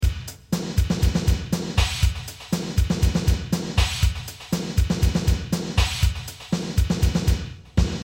蛇攻击动词脉冲
描述：蛇攻击的声音，为一个游戏（Allacrost的英雄）制作的。 用立体声驻极体麦克风 amp; Sharp MT190 MD.
标签： 攻击 嘶嘶声
声道立体声